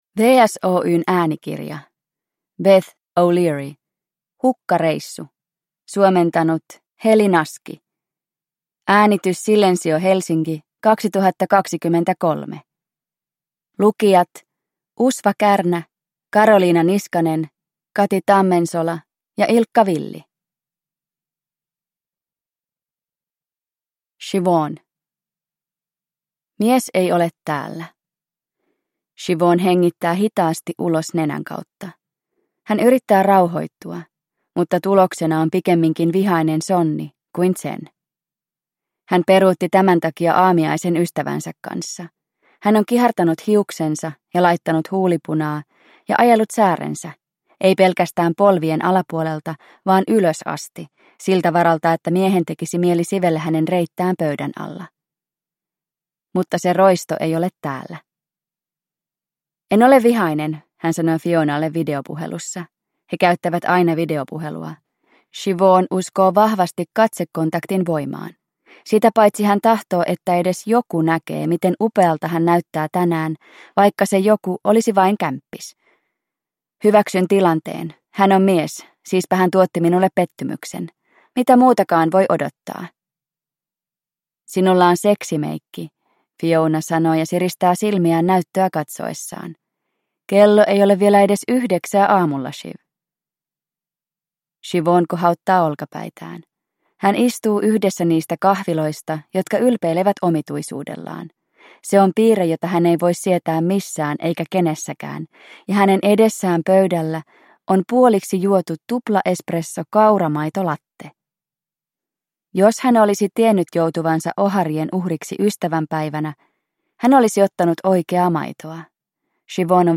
Hukkareissu – Ljudbok – Laddas ner